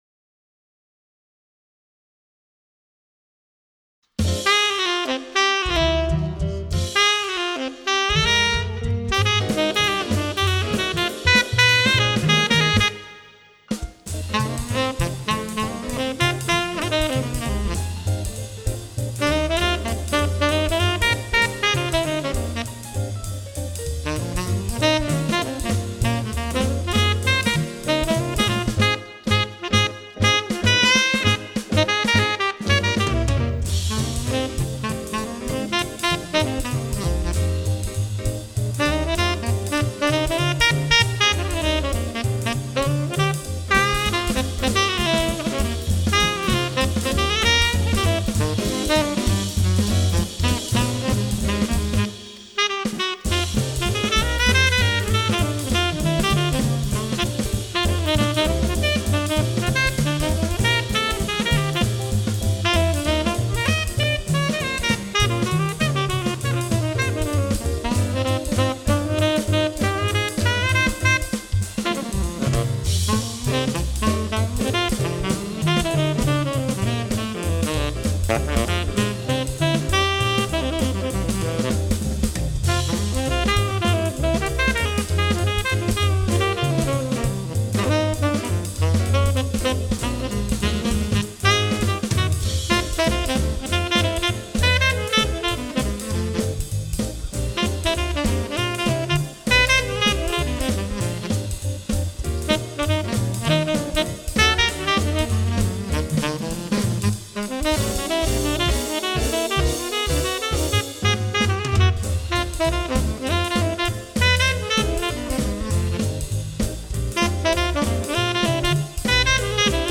12 Jazz Etudes Based on Popular Standards